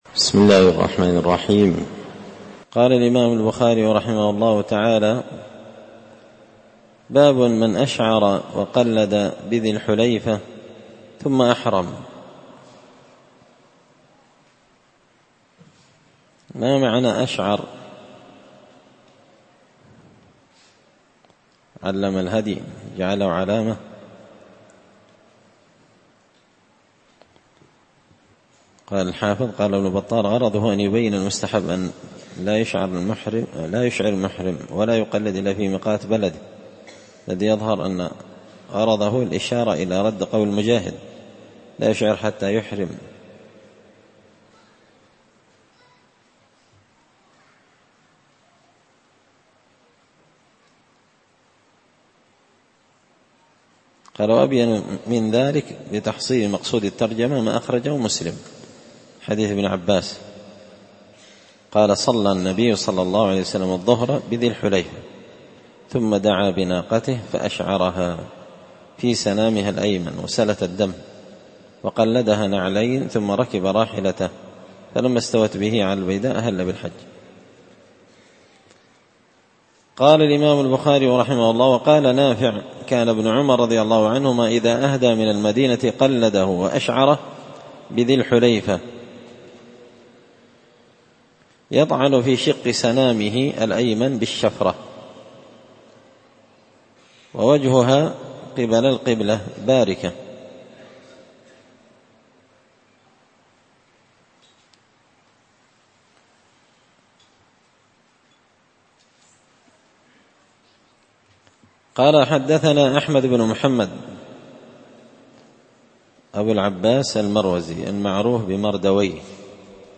كتاب الحج من شرح صحيح البخاري – الدرس 94